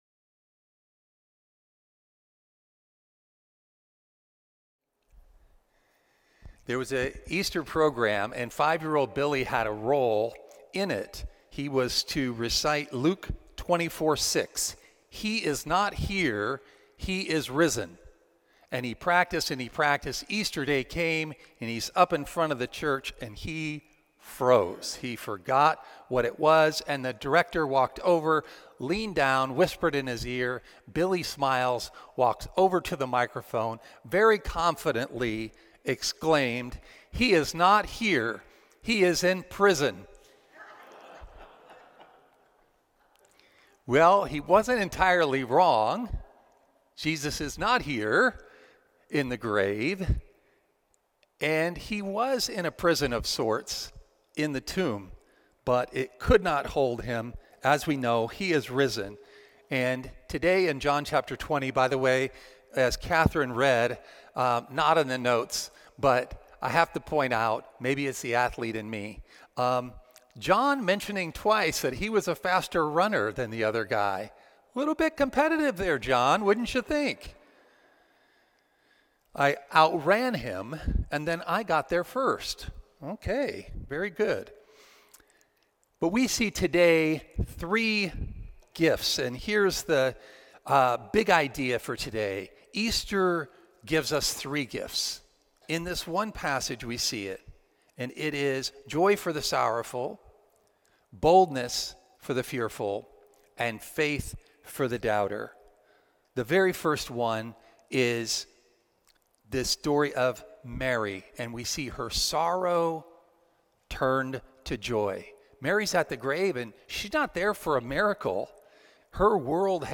Sermon-4-5-26.mp3